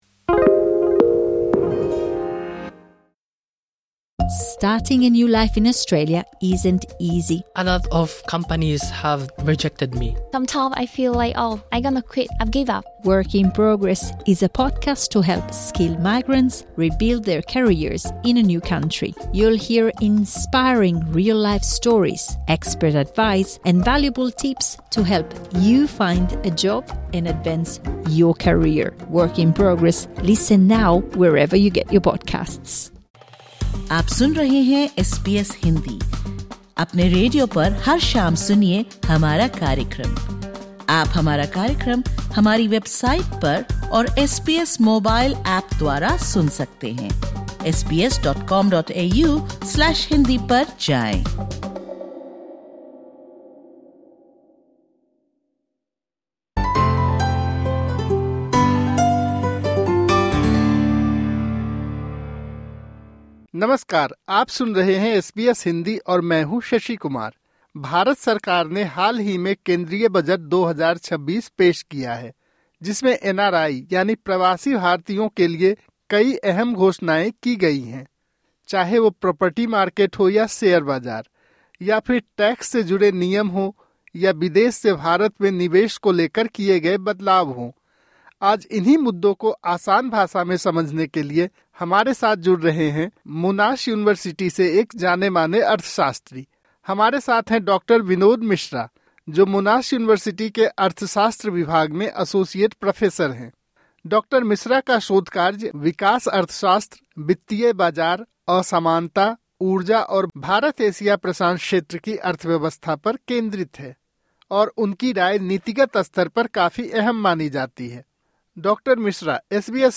India’s Budget 2026 introduces several significant measures aimed at easing investment and compliance for Non-Resident Indians (NRIs), including higher investment limits in Indian equities, simplified tax compliance for property transactions involving non-residents and changes to Tax Collected at Source (TCS) on overseas remittances. In a conversation with SBS Hindi